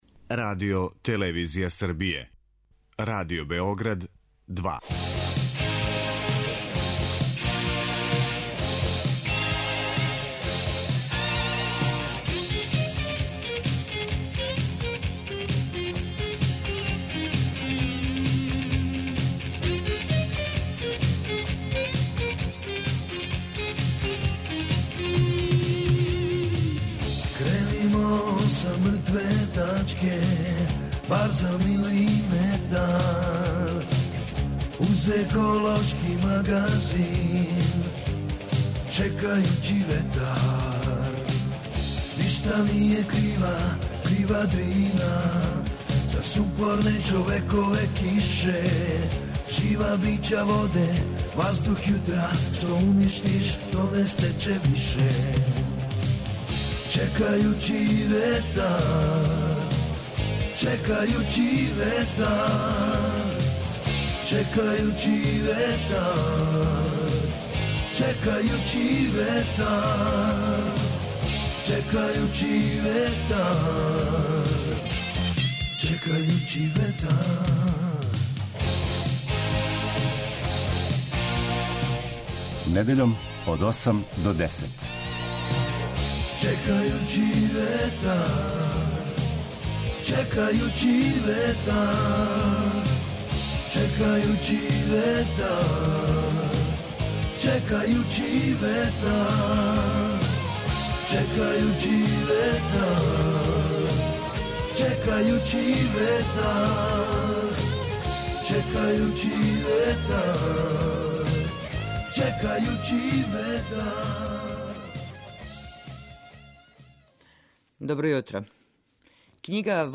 Еколошки магазин - о водопадима Србије и о загађењу у Кули и Врбасу